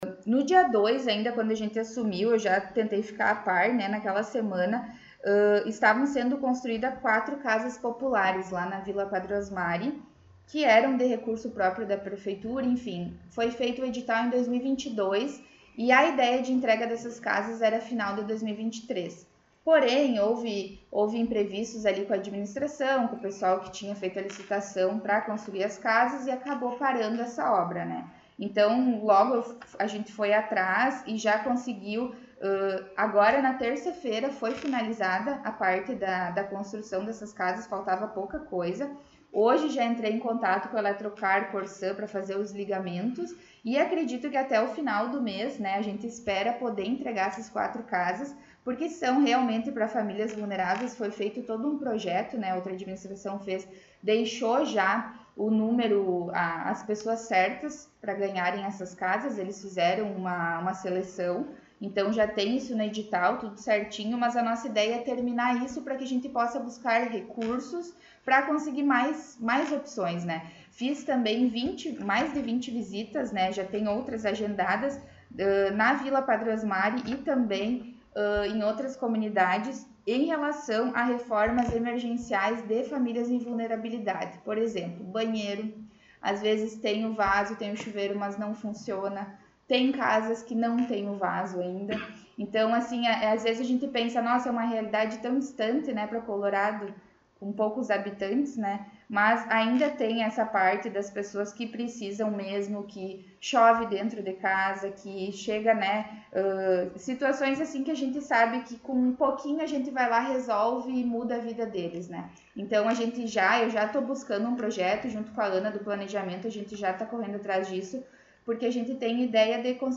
Secretária de Ação Social, Habitação e Saneamento concedeu entrevista